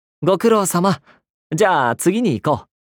文件:梅林胜利语音1.ogg